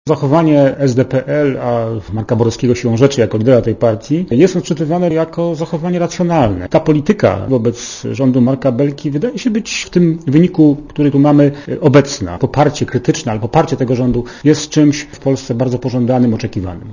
Mówi socjolog